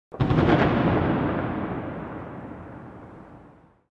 Huge Detonation Sound Effect
Loud, intense noise from a large explosion.
Huge-detonation-sound-effect.mp3